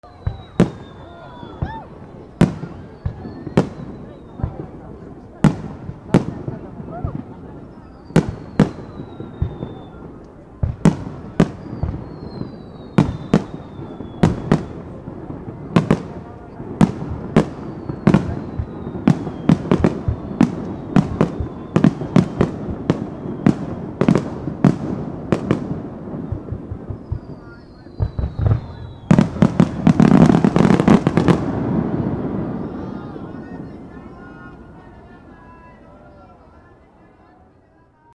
/ C｜環境音(人工) / C-45 ｜花火
花火 (雑音多し)
天神祭